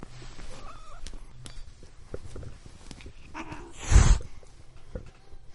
Descarga de Sonidos mp3 Gratis: gato 9.
descargar sonido mp3 gato 9